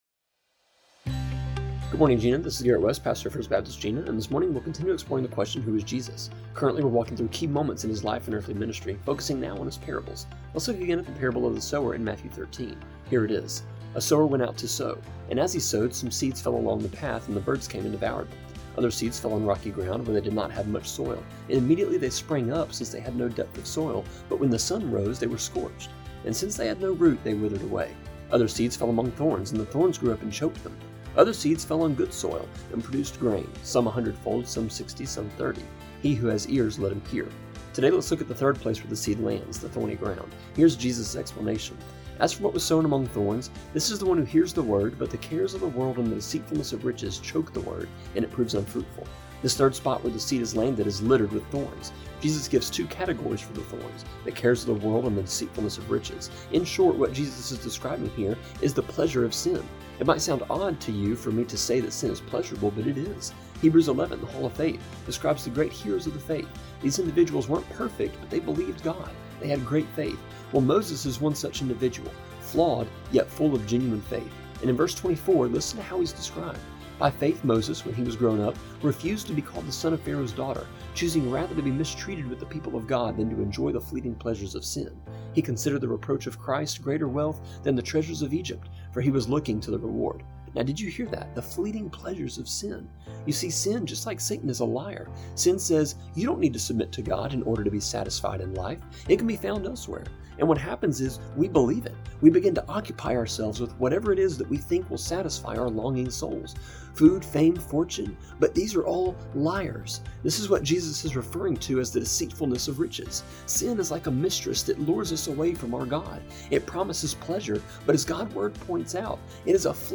A three minute (-ish) devotion that airs Monday through Friday on KJNA just after 7am.